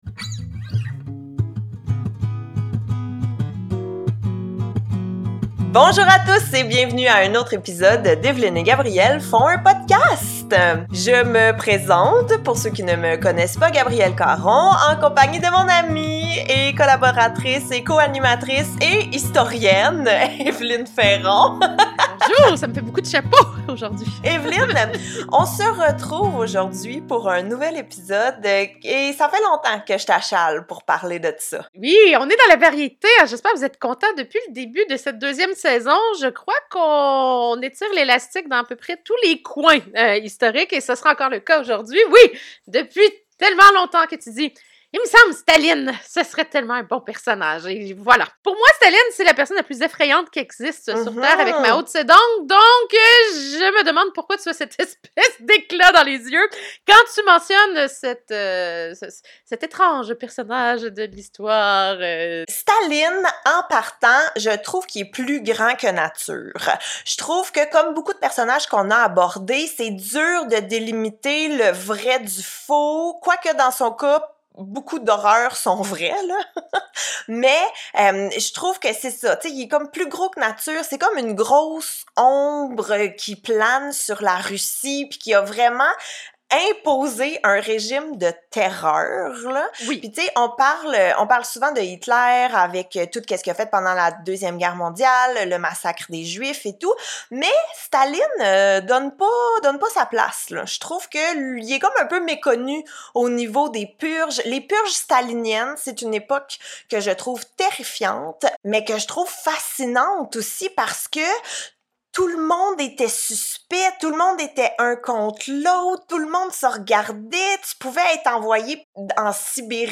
historienne
humoriste